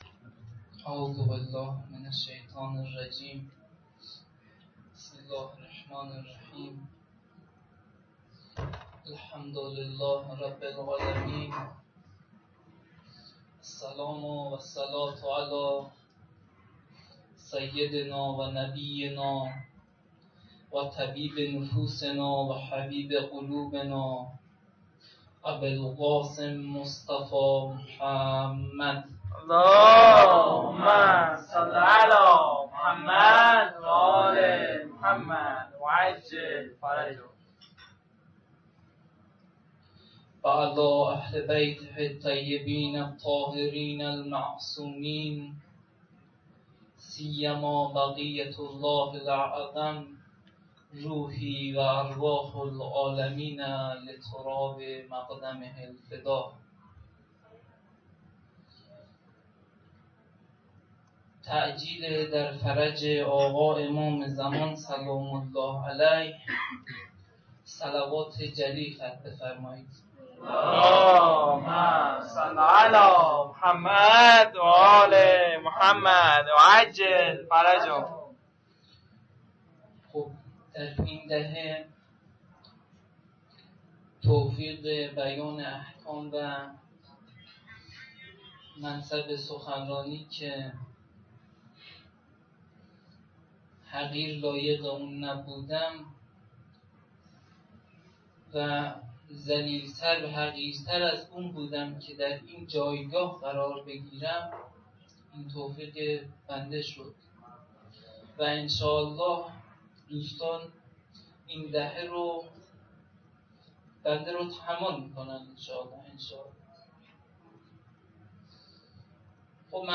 sokhanrani-3.mp3